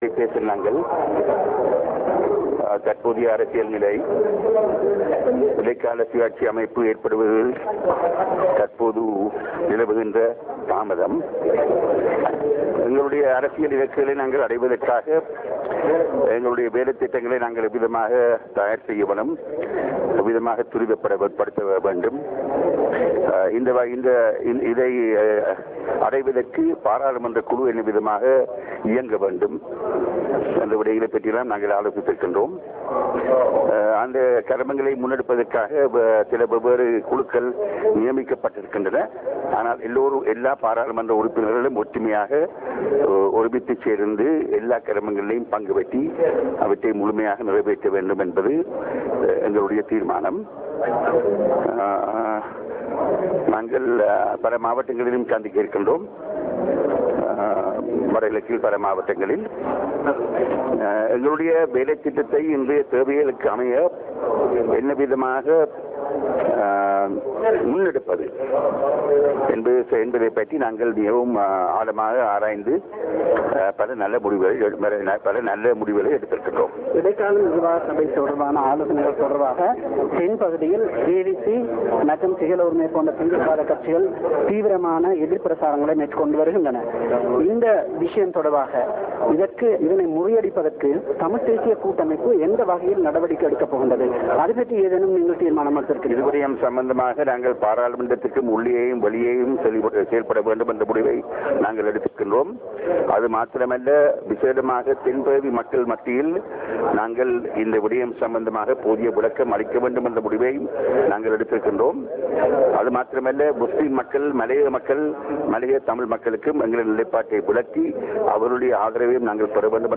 LTTE Political Head Mr. S. P. Thamilchelvan and Mr. Sampanthan met press briefly after TNA's meeting with the LTTE's Political Head at the Planning Secretariat of the Liberation Tigers in Kilinochchi.
Real Audio Icon Press briefing by Mr. R. Sambanthan (Tamil)